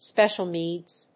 音節spècial néeds